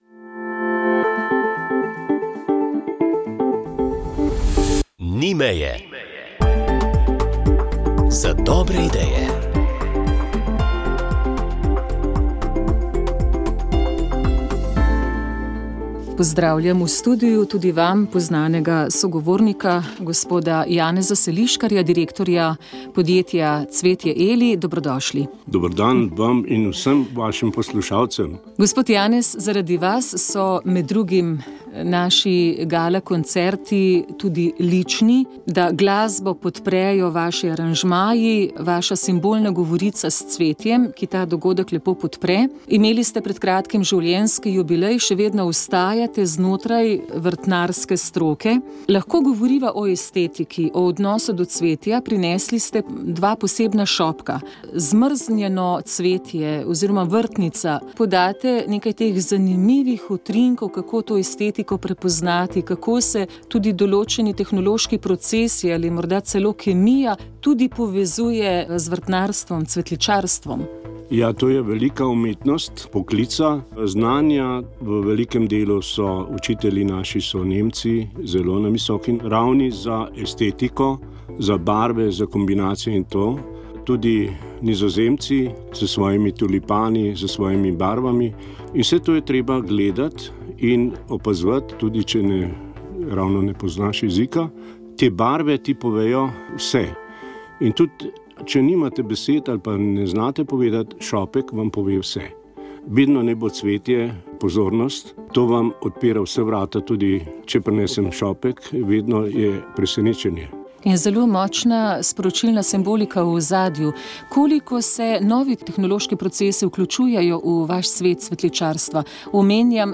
Zato v tokratni oddaji o ljubezni ... tako in drugače. Prebirali smo poezijo Vinka Möderndorferja iz njegove zbirke Dotikanja.